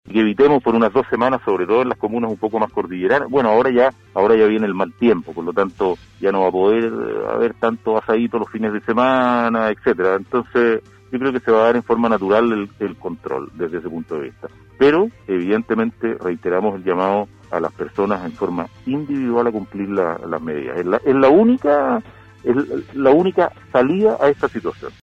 En conversación con Radio Sago, el Gobernador de Palena, José Luis Carrasco, hizo una revisión de la pandemia en dicha zona, recordando que al inicio de la contingencia, la zona no contaba con una residencia sanitaria, cuestión que cambió, ya que actualmente la provincia cuenta con un establecimiento para recibir a las personas que necesiten realizar su aislamiento, tanto para residentes o personas en tránsito La autoridad provincial dijo que si bien es cierto se cuenta con recursos para responder de manera oportuna a esta contingencia, como recurso aéreo, transporte, residencia sanitaria y una camper para hacer traslados con aislamiento de pacientes que necesiten ser derivados a Puerto Montt, es necesario extremar el autocuidado, porque es una responsabilidad individual.